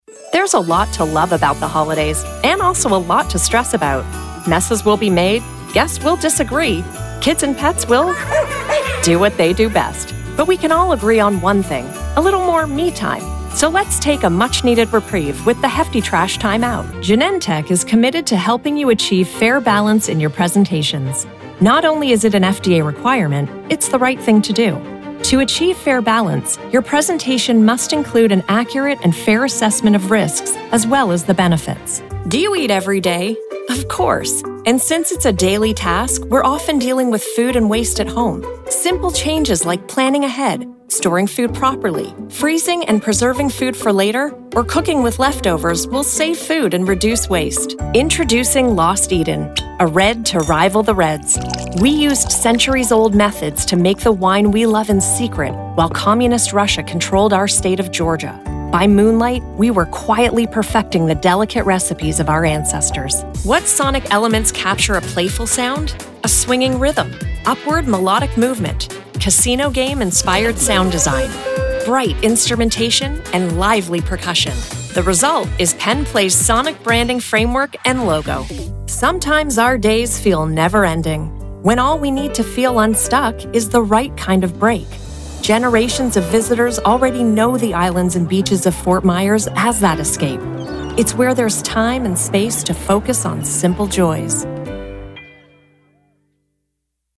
I bring clarity, warmth, and a voice that truly connects—so your message lands the way you intended.
Narration Demo
🎙 Broadcast-quality audio
(RODE NT1 Signature Mic + Fully Treated Studio)
Middle Aged Female